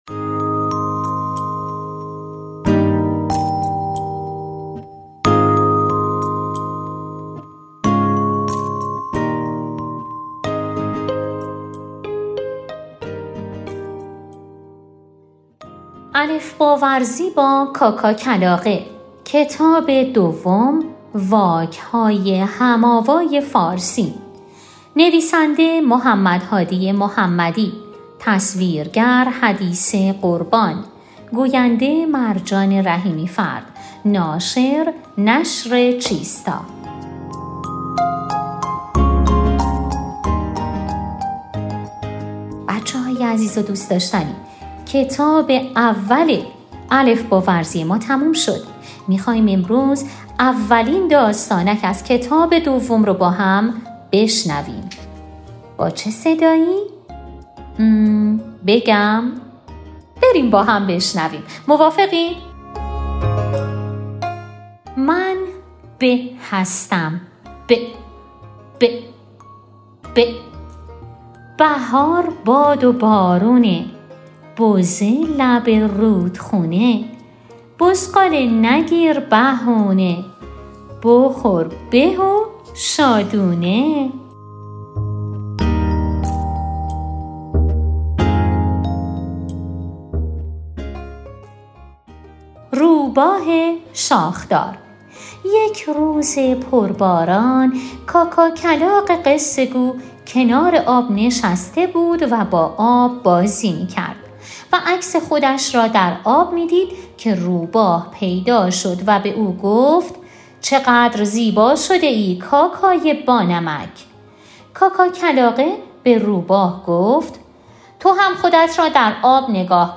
قرار است به همراه کاکاکلاغه ترانه و داستانک بخوانیم.